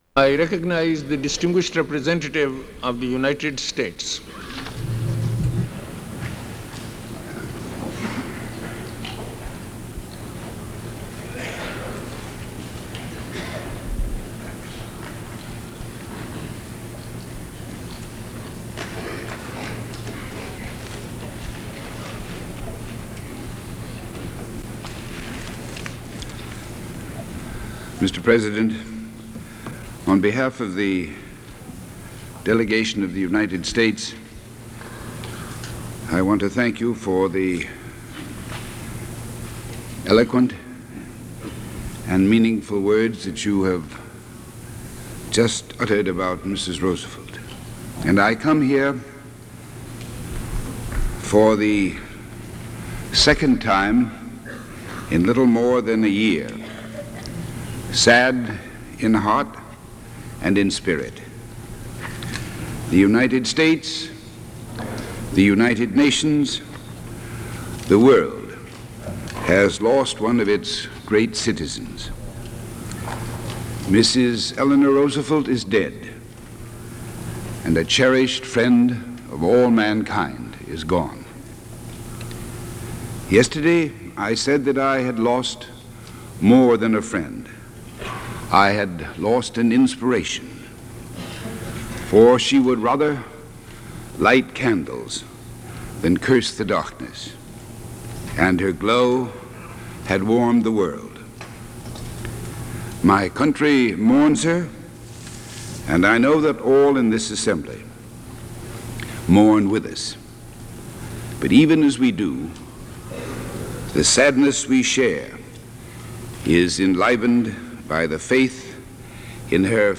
U.S. Ambassador to the United Nations Adlai Stevenson delivers a eulogy for Eleanor Roosevelt
Adlai Stevenson gives a eulogy for Eleanor Roosevelt at the United Nations. He expresses sadness at her death and speaks about her life and work.